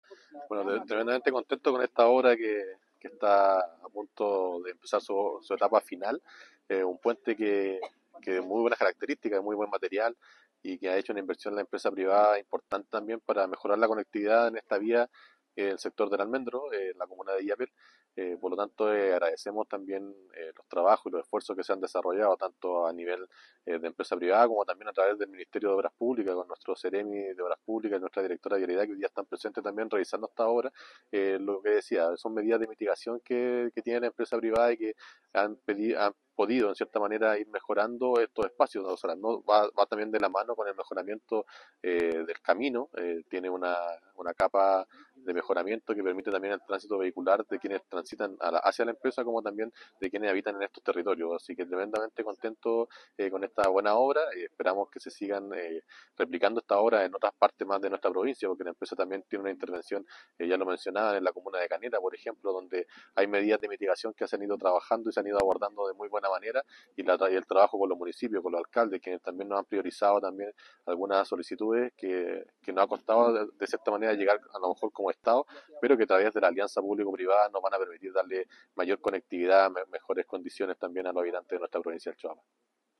Finalmente, el Delegado Provincial del Choapa, Jonatan Vega, agradeció el trabajo y valoró el hecho de que éstas obras aporten a la generación de empleos y reactivación económica de la provincia,